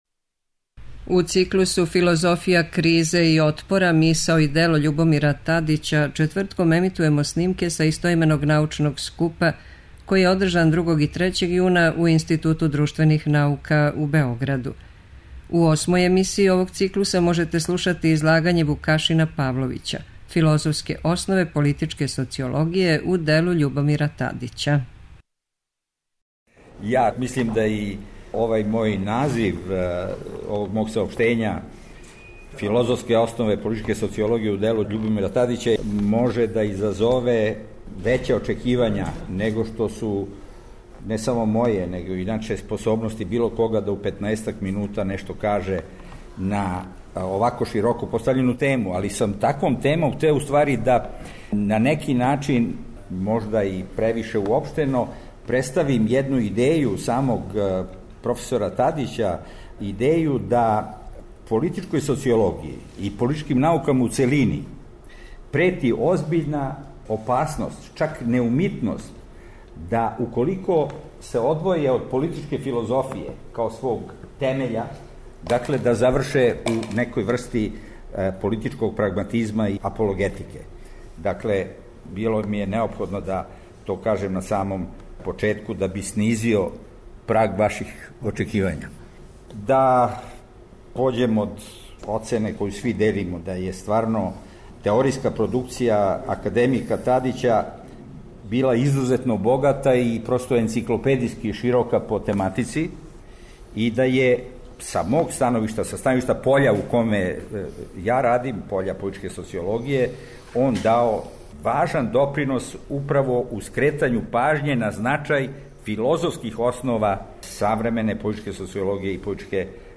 Научни скупoви